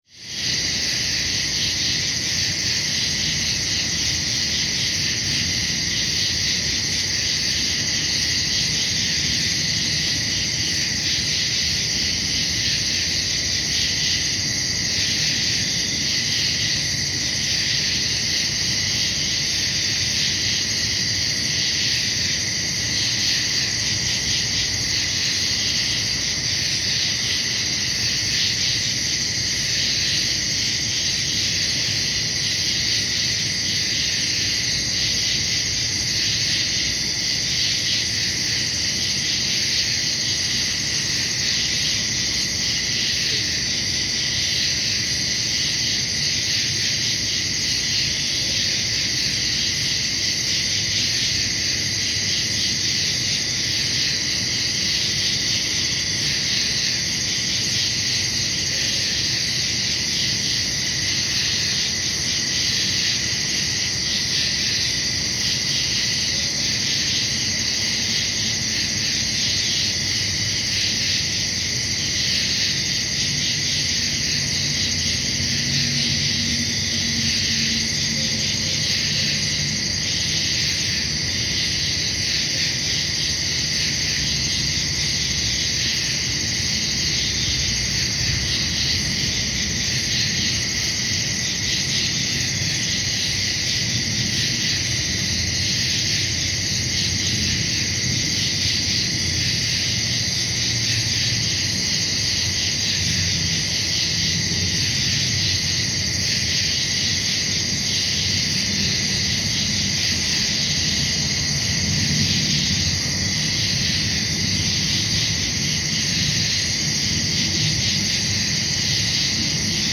Short version: 17 minutes, 25 mb Night time in the late summer. The location is on the edge of town. There are a lot of insects, and a few other animal sounds. There are also some passing cars, and some very high jet airplanes.